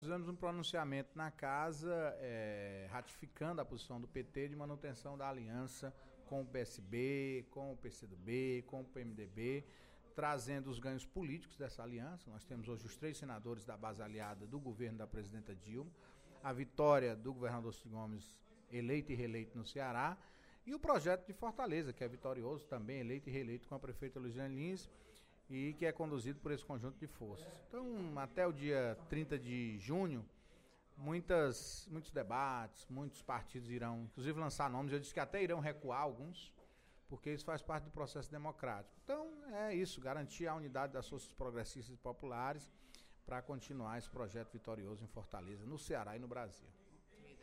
O líder do Governo na Casa, deputado Antonio Carlos (PT) afirmou, durante pronunciamento nesta terça-feira (13/03) na Assembleia, que o Partido dos Trabalhadores (PT) manterá unida e aliada a base progressista nas próximas eleições.